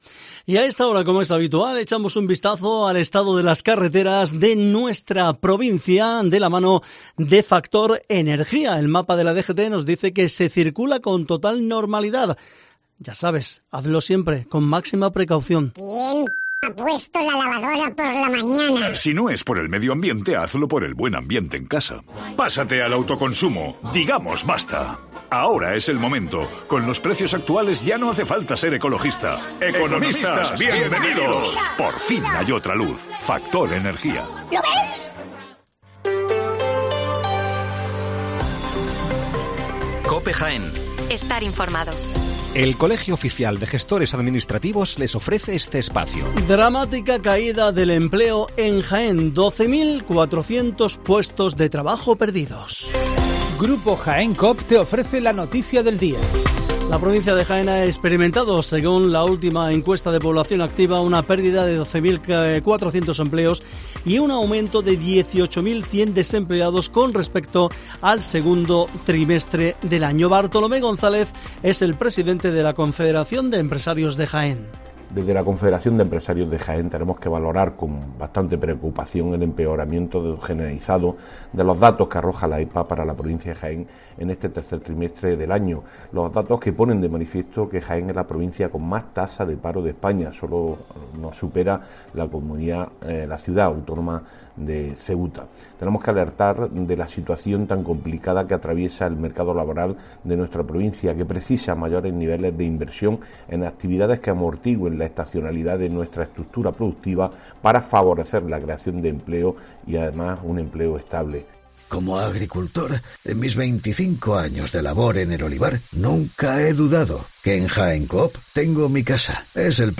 Las noticias de la mañana